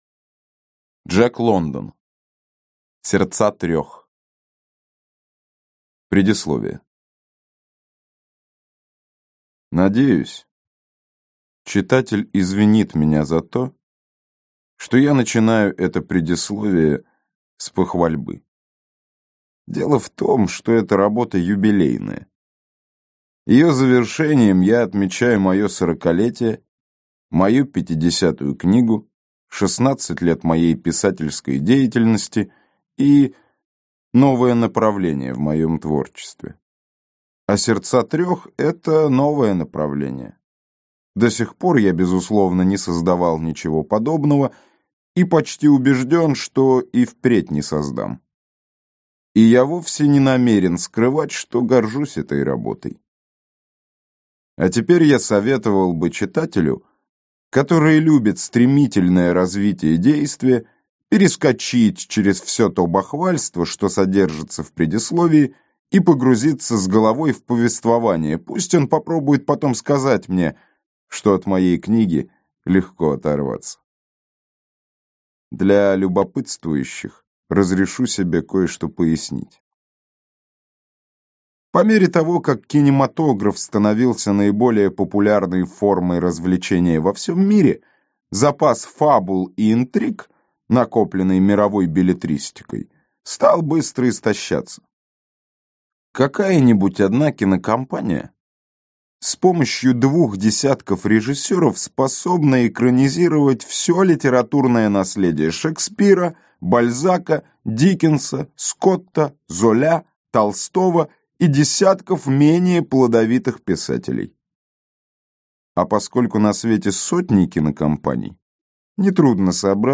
Аудиокнига Сердца трех | Библиотека аудиокниг